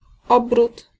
Ääntäminen
IPA : [ˌɹev.ə'luː.ʃən]